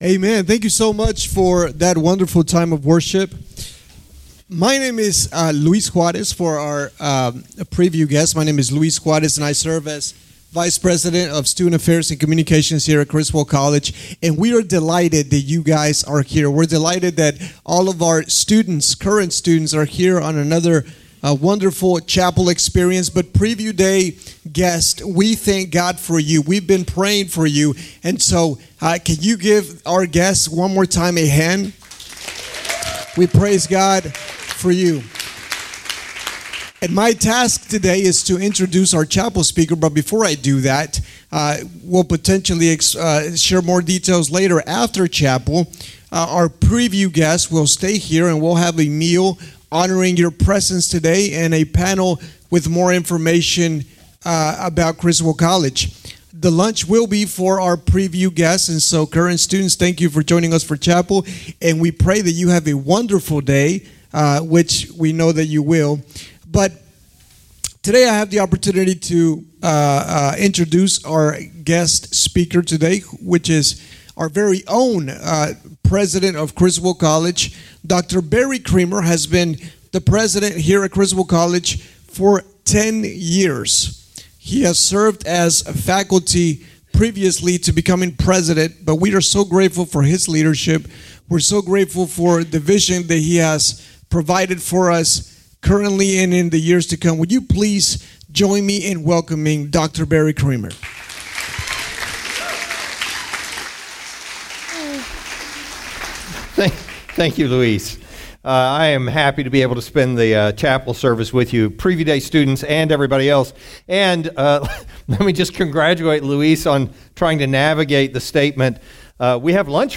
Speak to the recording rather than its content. Criswell College Chapel Service. Fall Preview Day 2024.